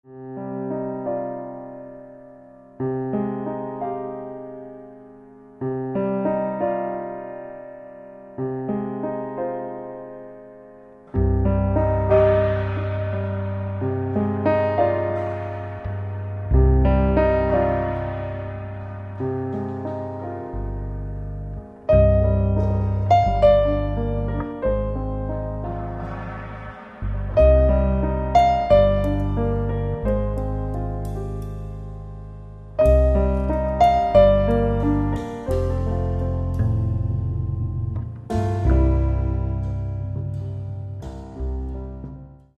Каталог -> Джаз и около -> Сборники, Джемы & Live